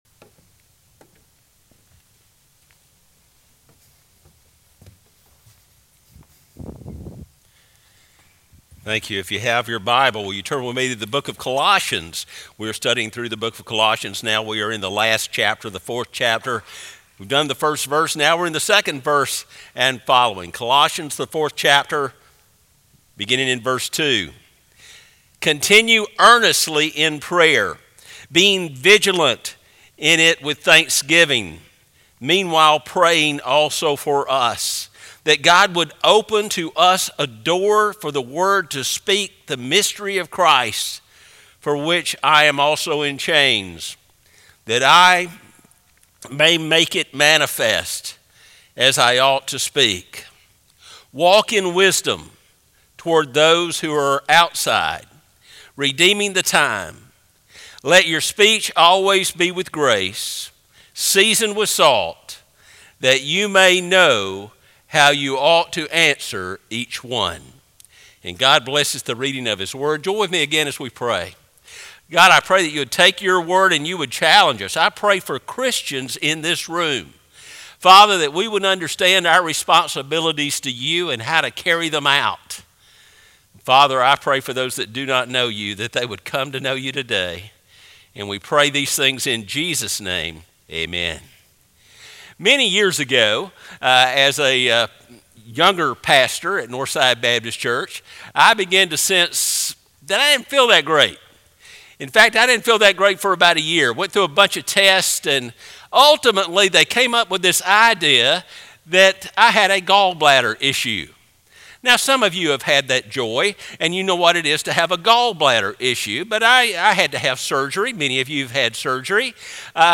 Sermons - Northside Baptist Church